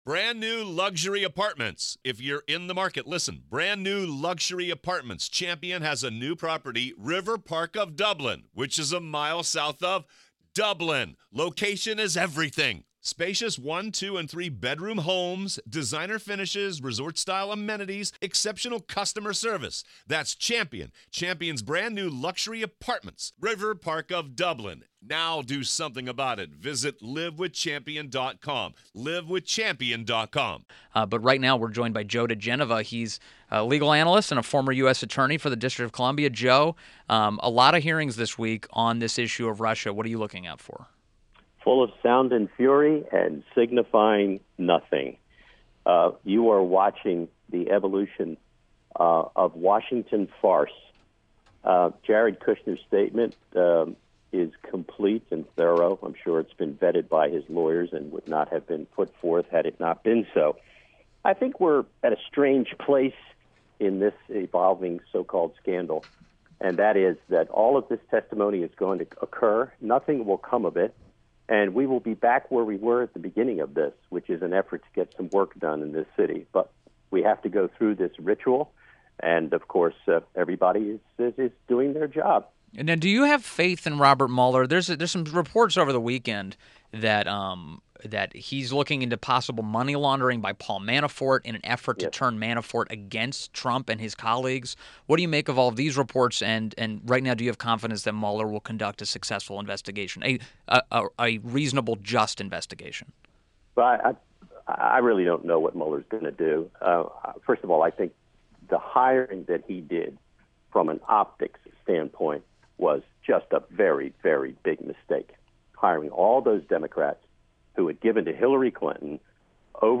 WMAL Interview - JOE DIGENOVA 07.24.17
JOE DIGENOVA – legal analyst and former US Attorney to the District of Columbia